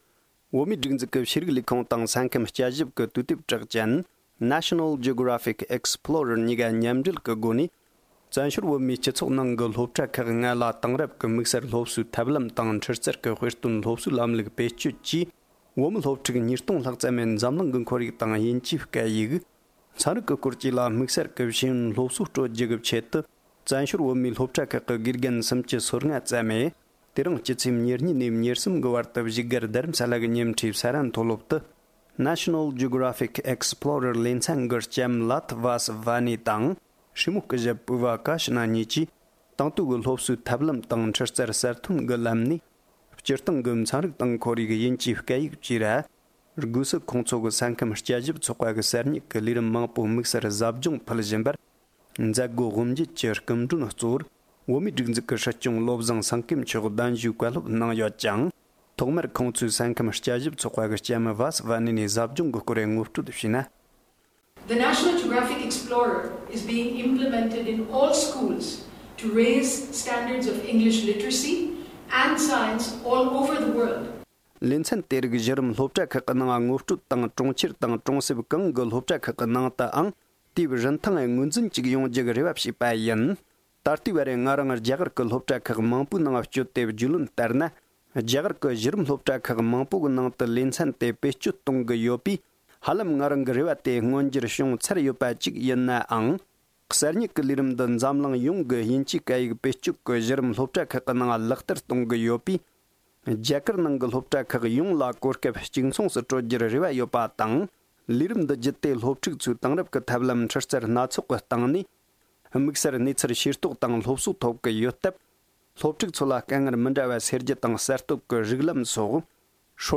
དགེ་རྒན་ཟབ་སྦྱོང༌ཐོག་གསུང་བཤད།
སྒྲ་ལྡན་གསར་འགྱུར།